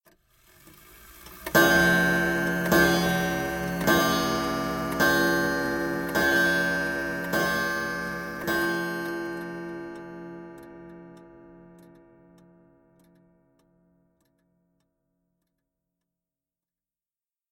Fading Chime
Tags: clock